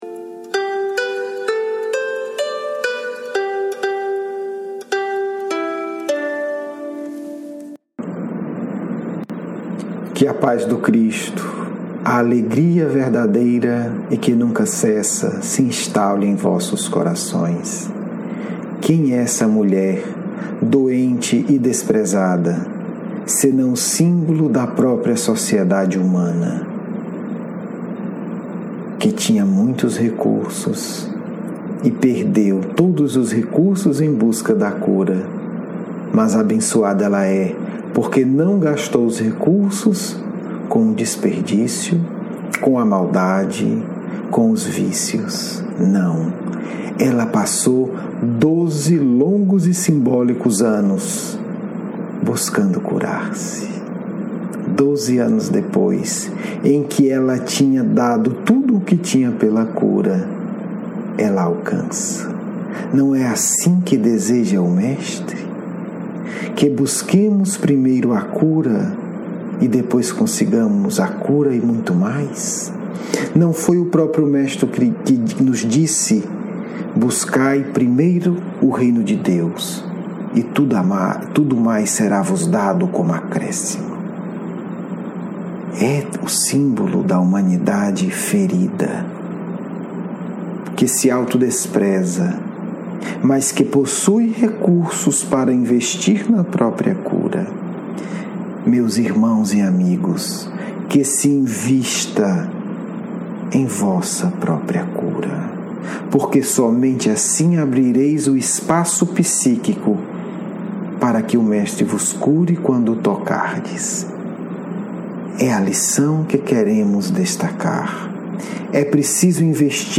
Diálogo mediúnico